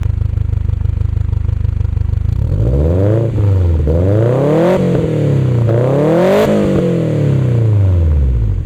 全体的に爆音ではないけれど、ボリューム大きめって感じです。
アイドリング→レーシング 436KB
音は、FLATエキマニType3と、RM-01A Tiの組み合せです。